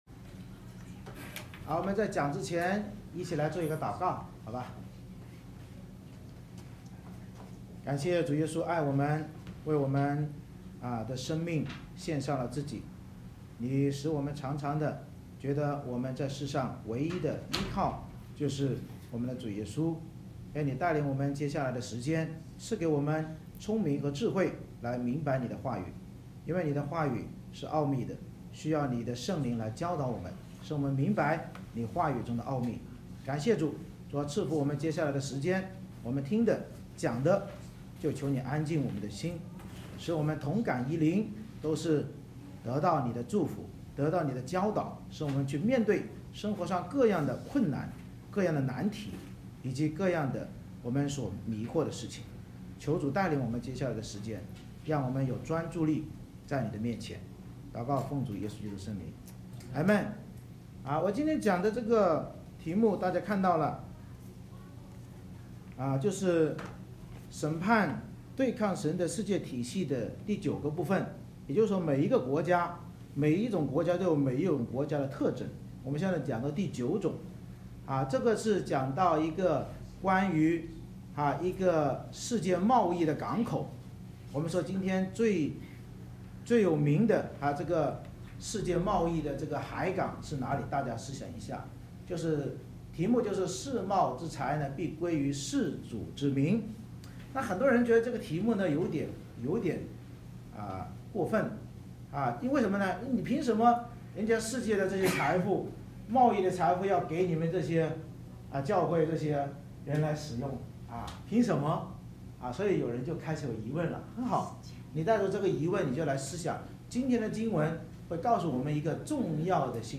以赛亚书Isaiah 23:1-18 Service Type: 主日崇拜 先知领受推罗的默示，宣告世界贸易的兴衰都由神掌管，神能使用任何国家或地区的财富为忠心爱主事主之民服务来达成祂的福音计划。